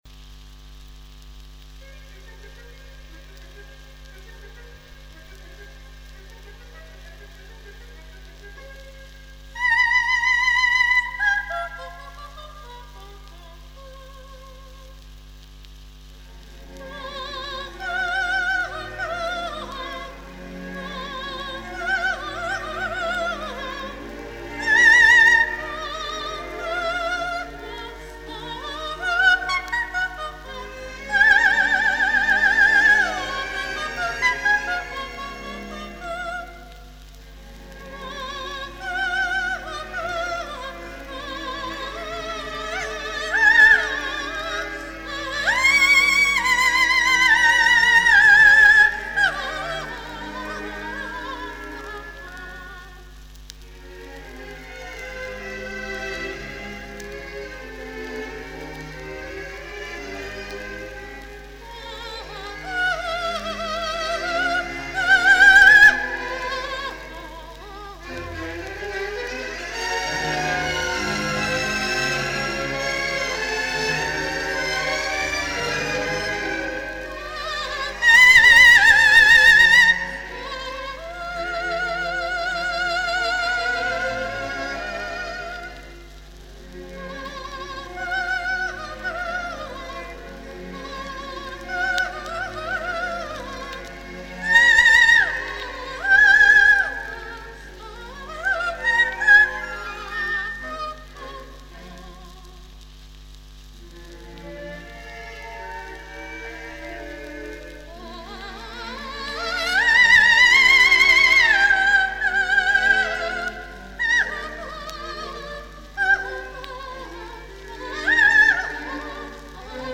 ВОКАЛИЗ – это произведение, написанное для  голоса без слов.
05 Gohar Gasparyan - Концерт для голоса и оркестра part 2 (R.Gliere)